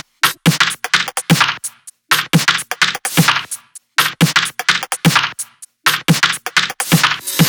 VFH1 128BPM Southern Kit